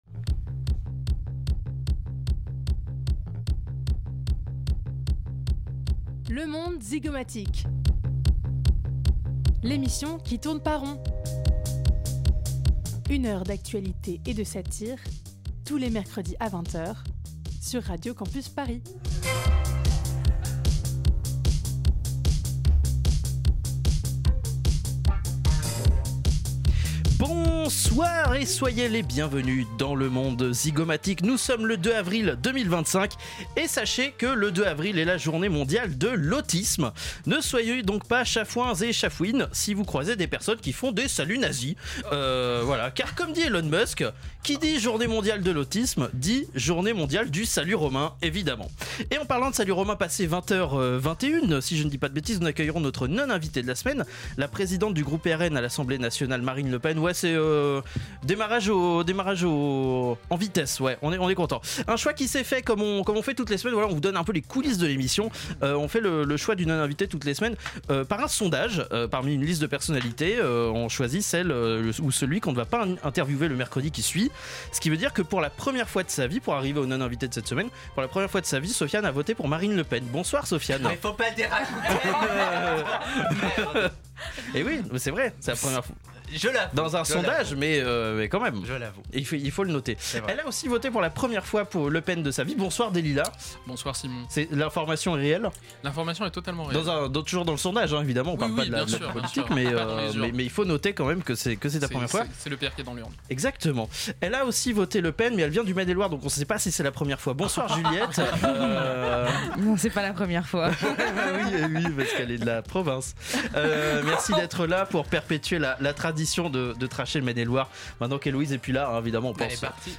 Une émission où on dira en toute impartialité : CHEEHH